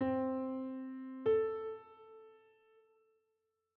Major 6th
C-Major-Sixth-Interval-S1.wav